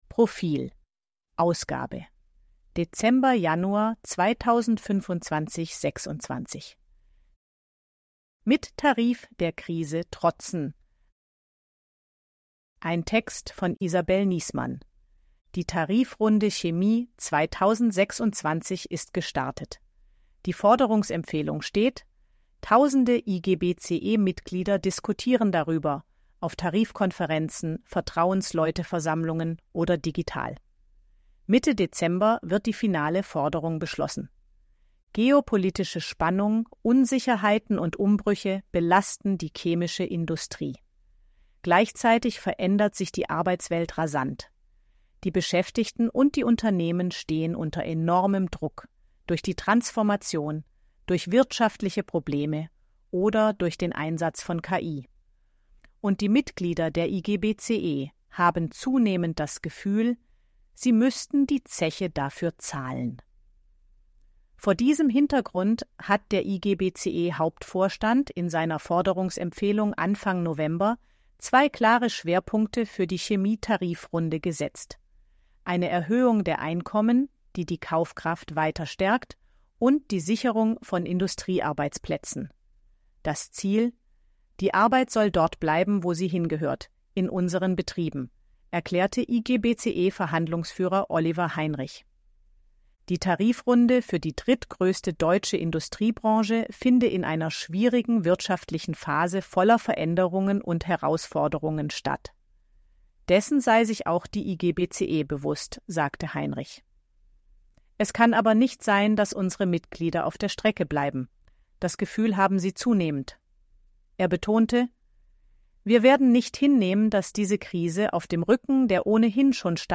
Artikel von KI vorlesen lassen
ElevenLabs_256_KI_Stimme_Frau_HG-Story.mp3